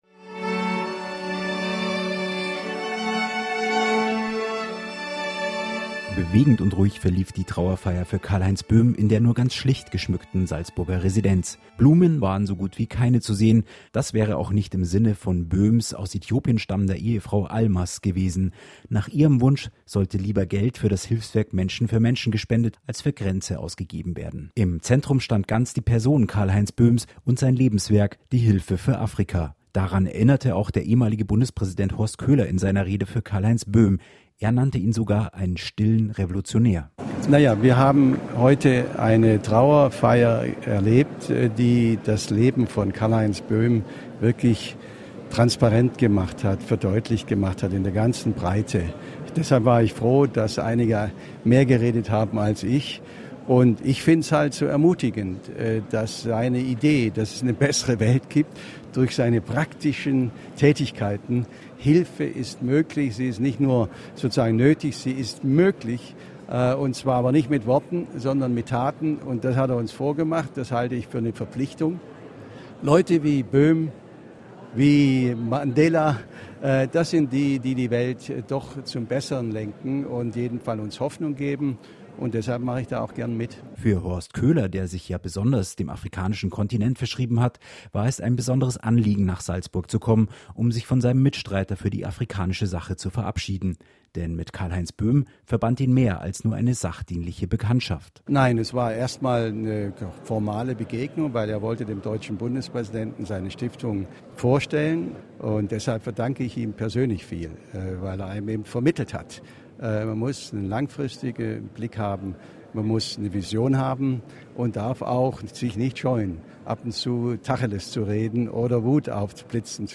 Trauerfeier-Karlheinz-Boehm.mp3